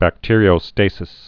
(băk-tîrē-ō-stāsĭs)